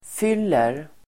Uttal: [f'yl:er]